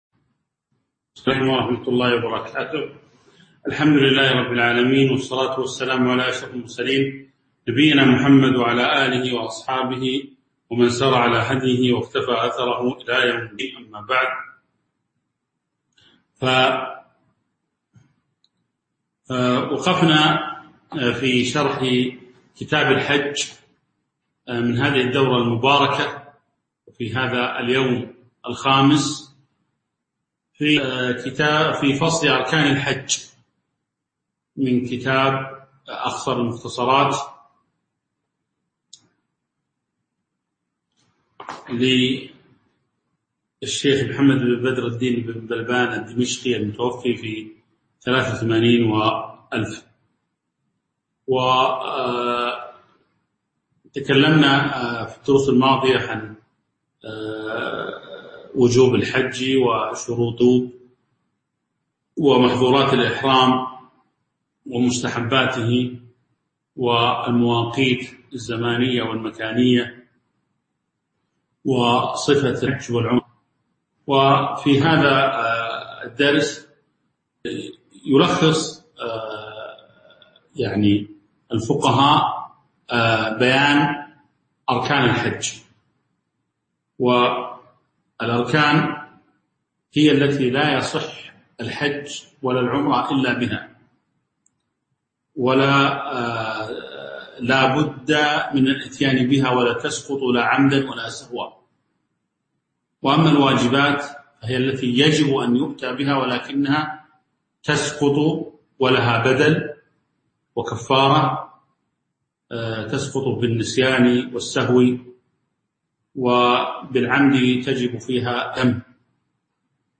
تاريخ النشر ٢٥ شوال ١٤٤١ هـ المكان: المسجد النبوي الشيخ